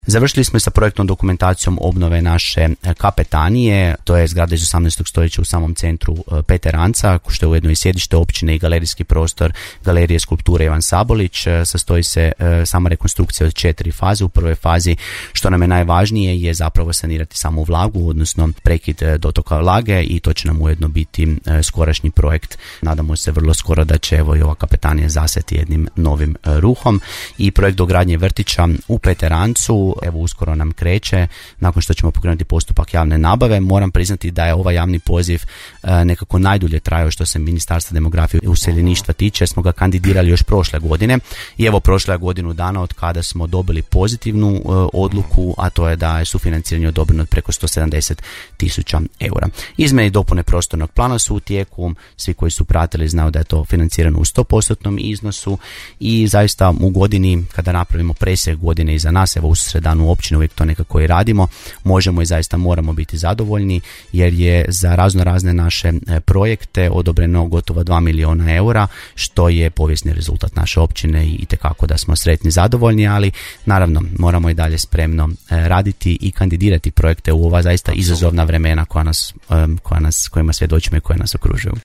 Načelnik Općine Peteranec Ivan Derdić, bio je ovotjedni gost emisije Susjedne općine, koji se na početku zahvalio mještankama i mještanima na ponovnom izboru njega kao načelnika na održanim Lokalnim izborima.